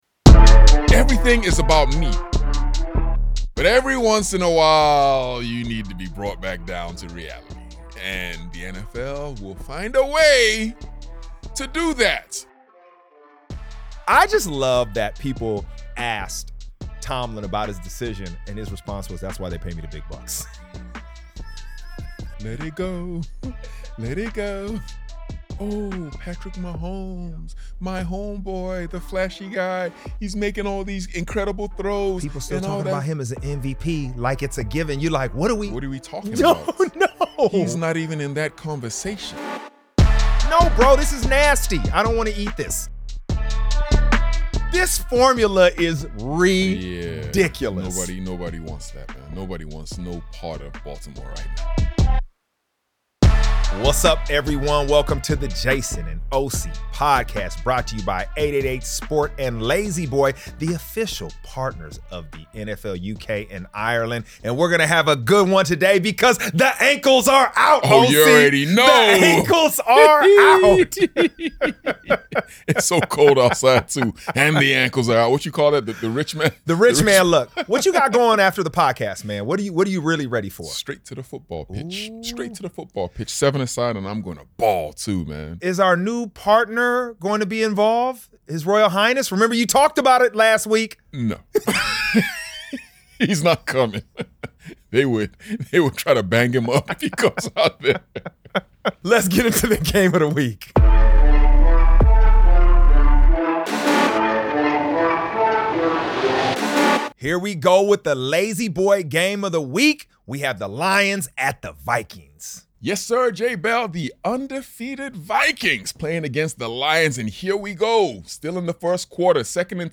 In Week 7 of the NFL, Jason Bell and Osi Umenyiora break down all the action, brought to you by 888sport & La-Z-Boy—official partners of NFL UK and Ireland.